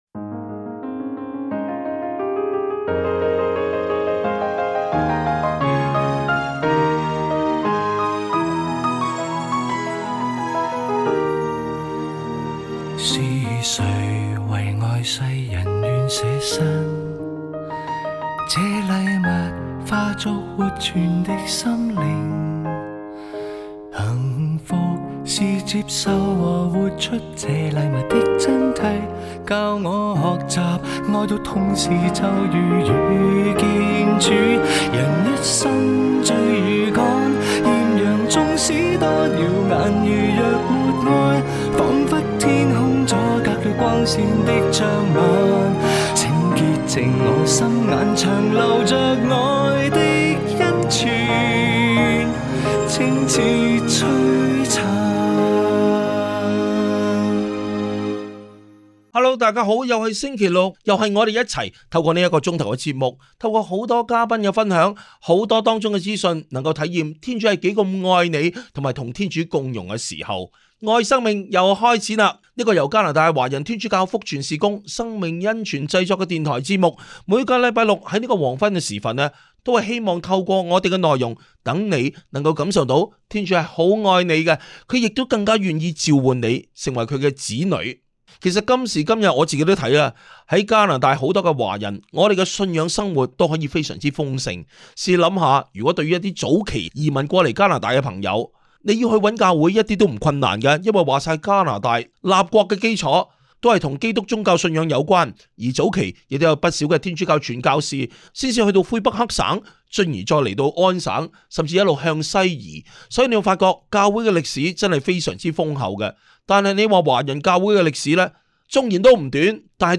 「愛 • 生命」- 二零二六年三月十四日廣播節目全集 Radio broadcast – Full episode (March 14, 2026)